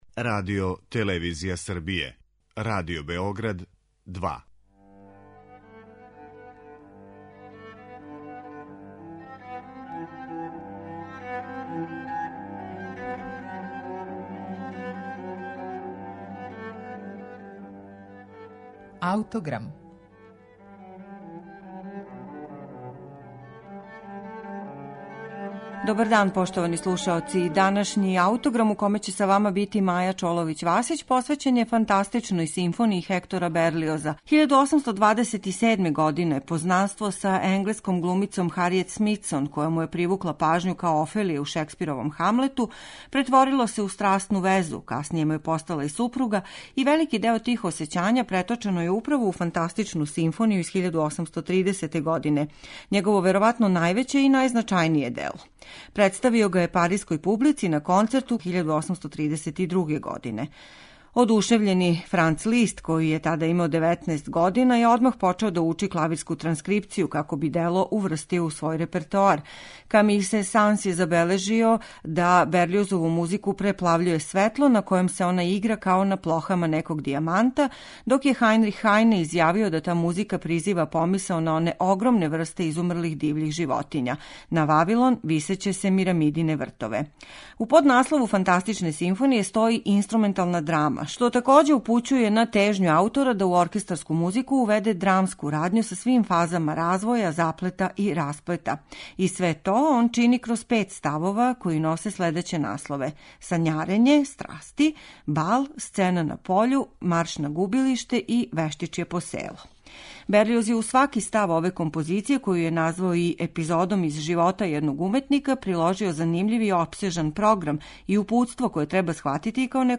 Ову револуционарну композицију на пољу програмске симфонијске музике, слушећете данас у извођењу Симфонијског оркестра из Монтреала, којим диригује Шарл Дитуа.